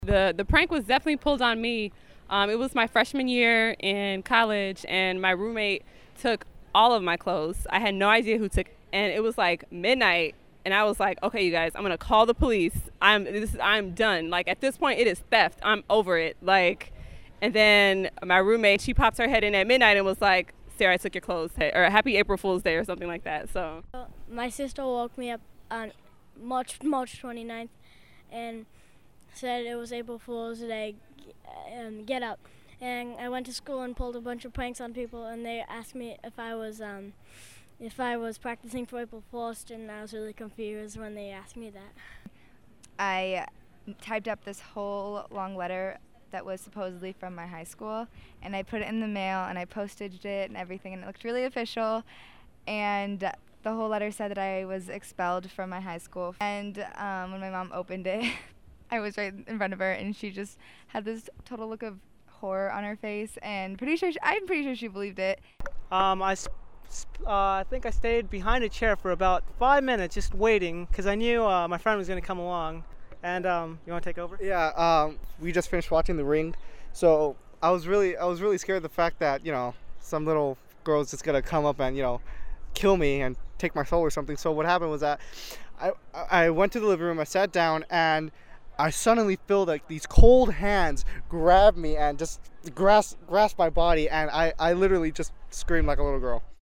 aprilfoolvoxpop.mp3